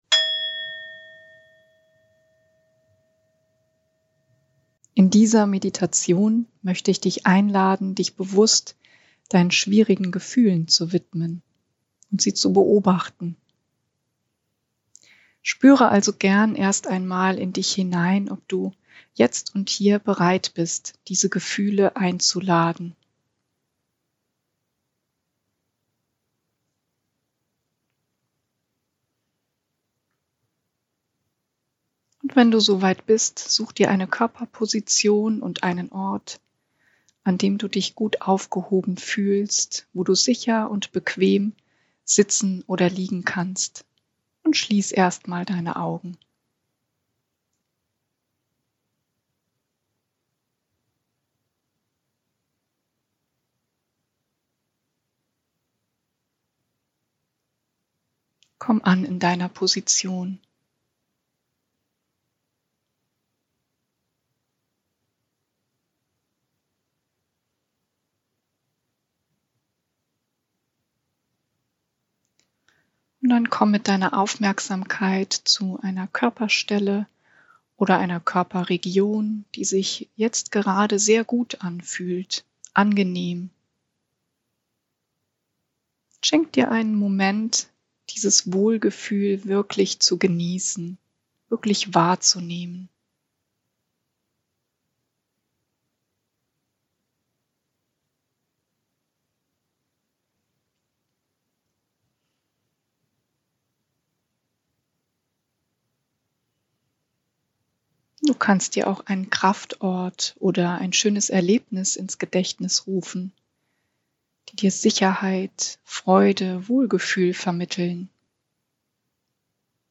meditation-gefuehle-beobachten.mp3